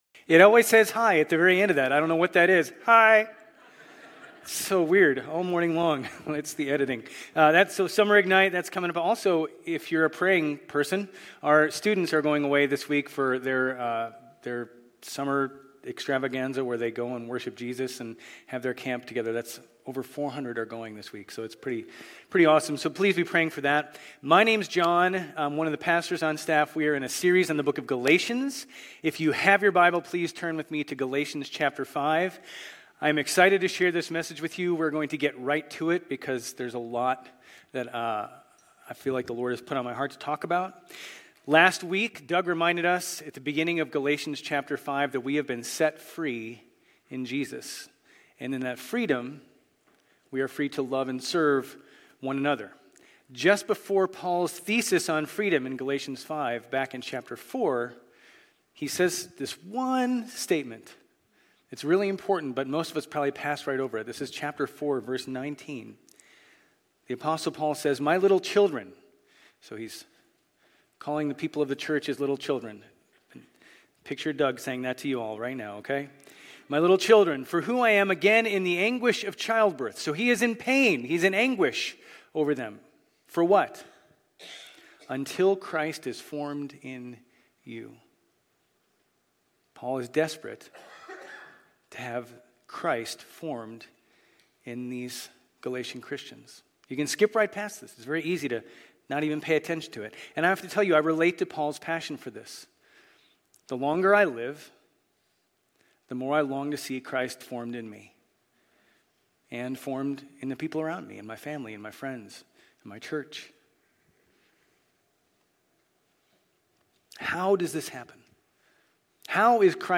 Grace Community Church Old Jacksonville Campus Sermons Galatians 5:16-26 Jun 03 2024 | 00:32:58 1x 00:00 / 00:32:58 Subscribe Share RSS Feed Share Link Embed